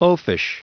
Prononciation du mot oafish en anglais (fichier audio)
Prononciation du mot : oafish